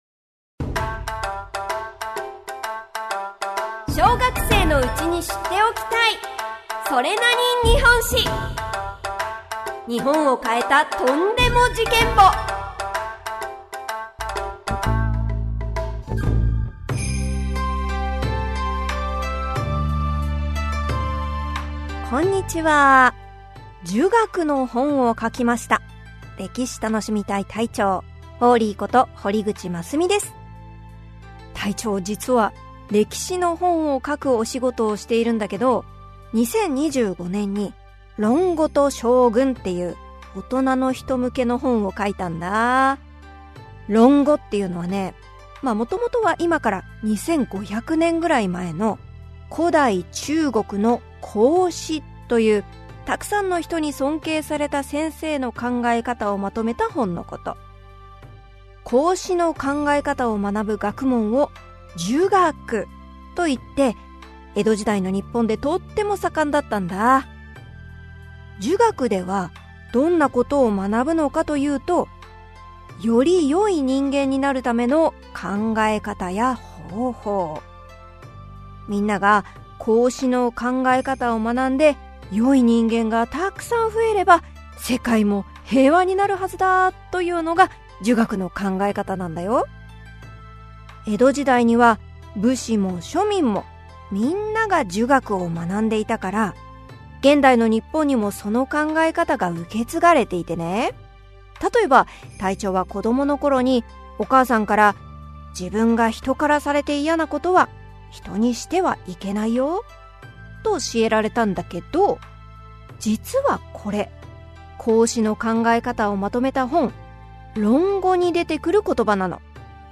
[オーディオブック] 小学生のうちに知っておきたい！